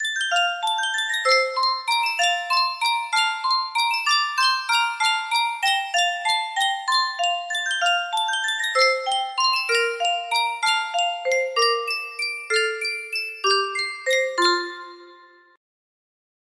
Yunsheng Music Box - The First Noel 6227 music box melody
Full range 60